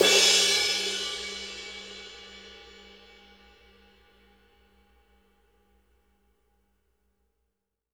CRASH A   -R.wav